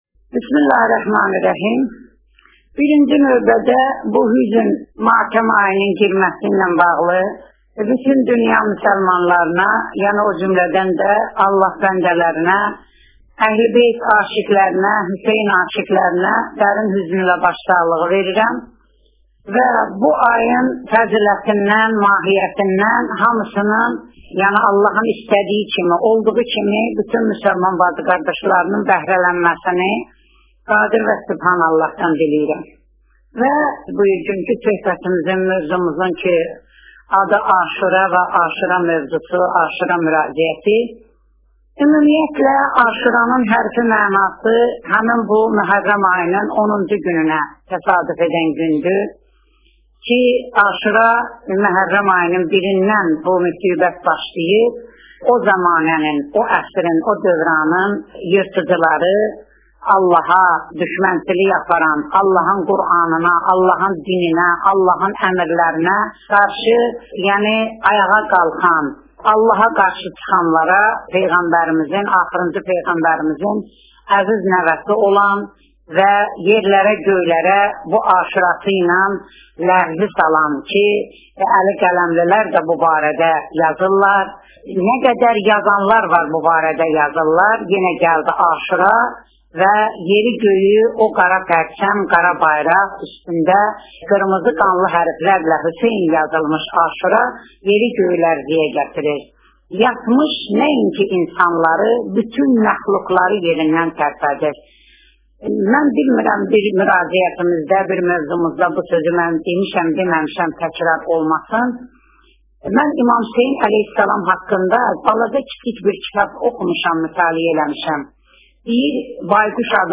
Aşura münasibəti ilə reportaj (Audio)